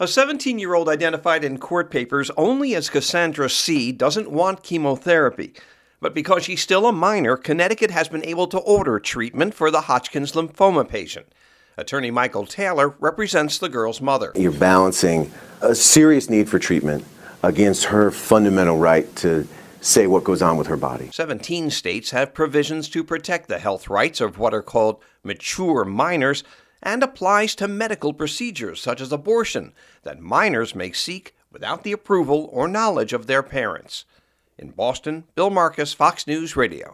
REPORTS: